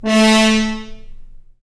Index of /90_sSampleCDs/Roland L-CDX-03 Disk 2/BRS_F.Horns FX+/BRS_FHns Mutes
BRS F.HRNA0F.wav